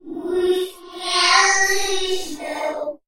Звуки страшного голоса
Ребенок из загробного мира напевает песенку